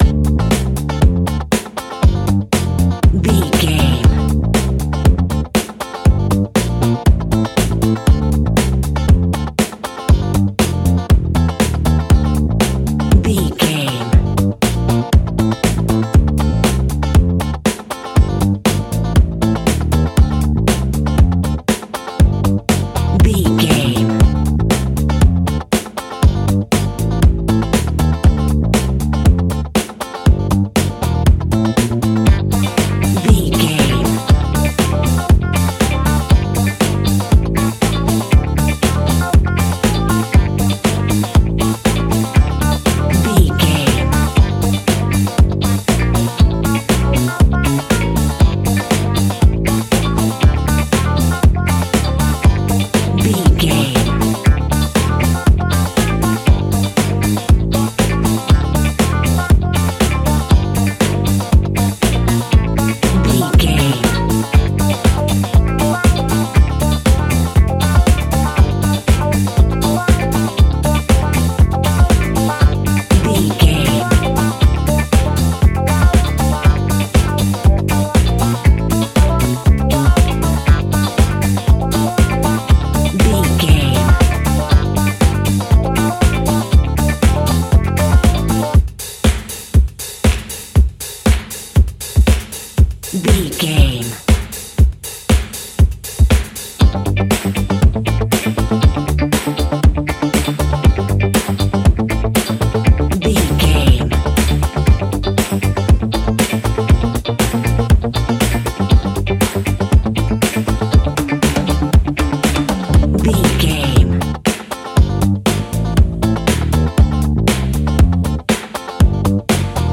Ionian/Major
D
house
synths
techno
trance
instrumentals